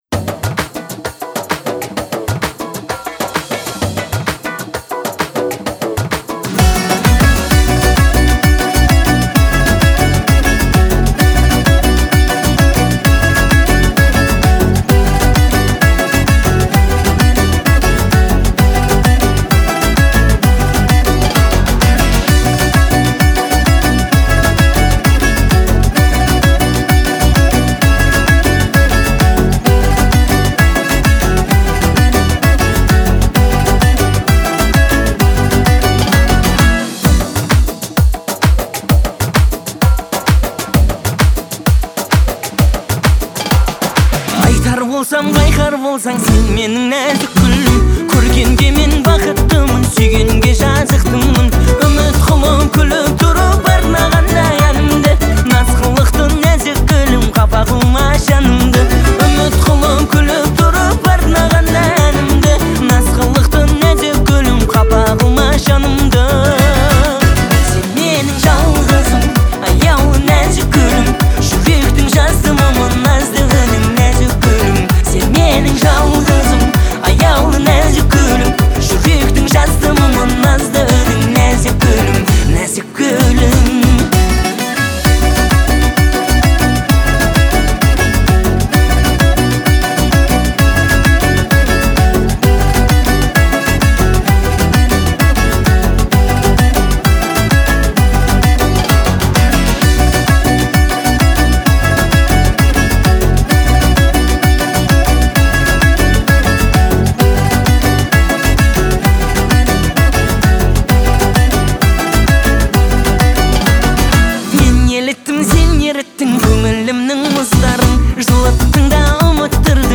это трогательная песня в жанре казахской поп-музыки